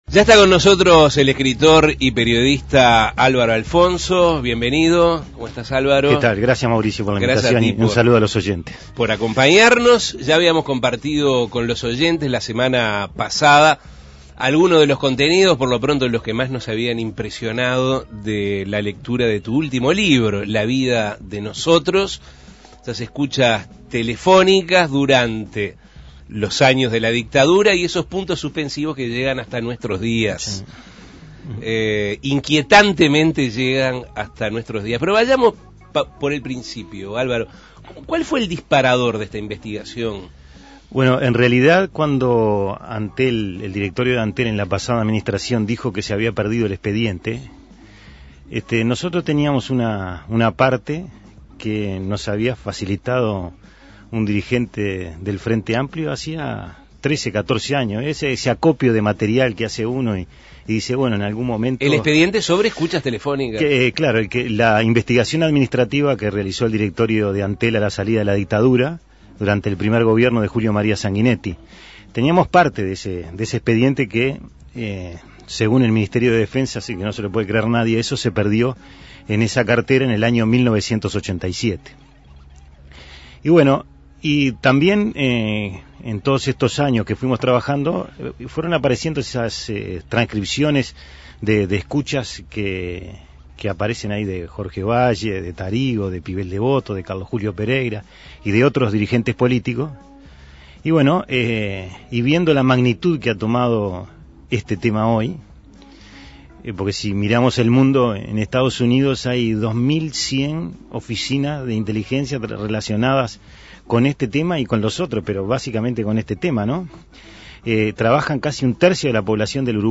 Asuntos Pendientes recibió al periodista, escritor y alcalde de Aguas Corrientes, Álvaro Alfonso.
Entrevistas Álvaro Alfonso contó La Vida de Nosotros Imprimir A- A A+ Asuntos Pendientes recibió al periodista, escritor y alcalde de Aguas Corrientes, Álvaro Alfonso.